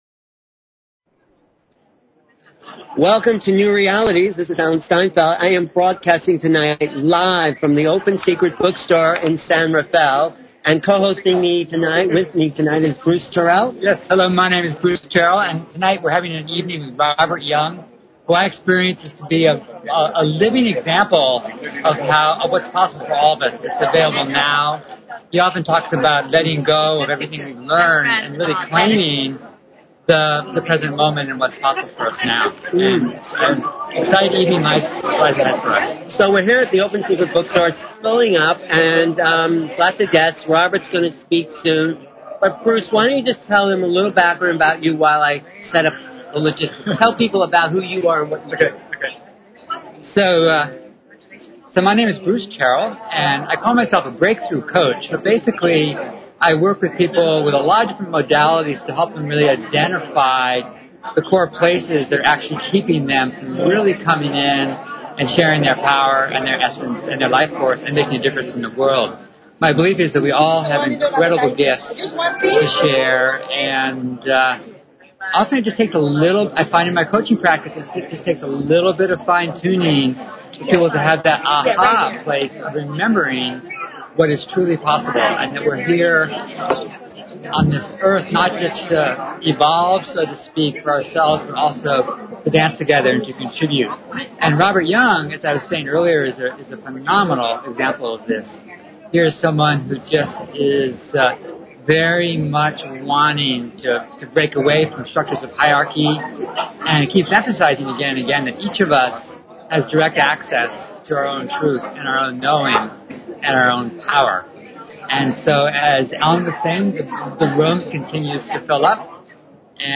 Talk Show Episode, Audio Podcast, New_Realities and Courtesy of BBS Radio on , show guests , about , categorized as
LIve broadcast from the Open Secret bookstore in San Rafel, CA.